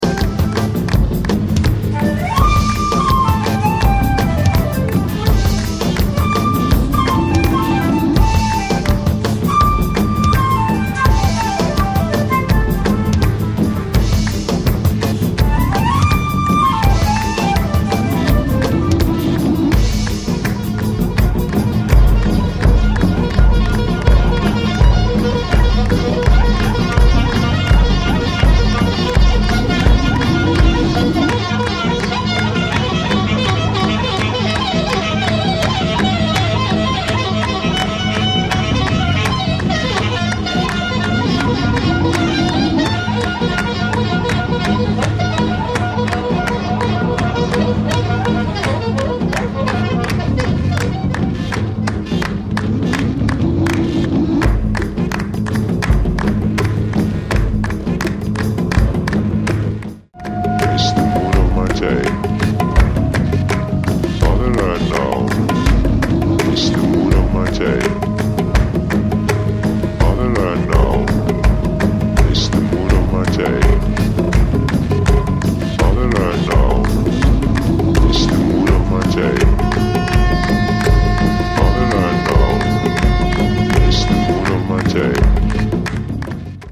ultra obscure jazz-trance-world-tribalized